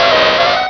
pokeemerald / sound / direct_sound_samples / cries / ponyta.aif
ponyta.aif